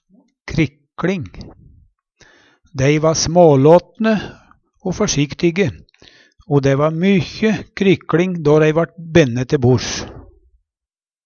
krikLing - Numedalsmål (en-US)